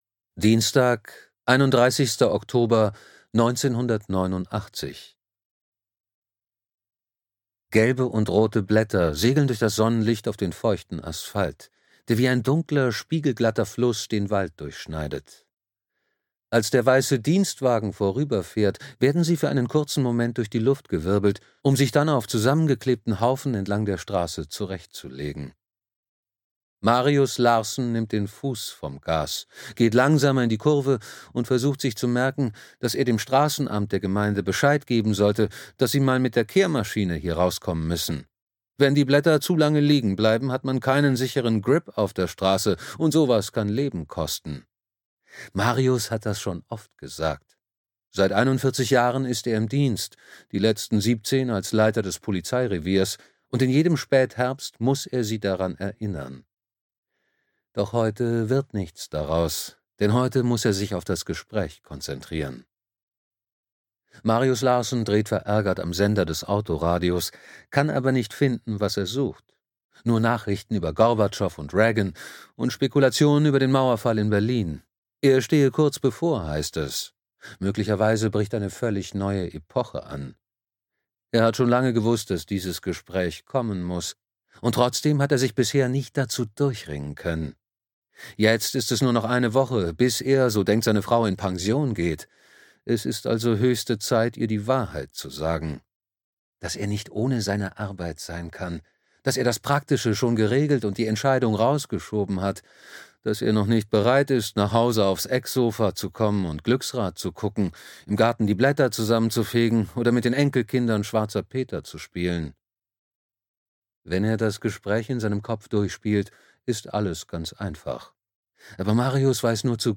Der Kastanienmann (DE) audiokniha
Ukázka z knihy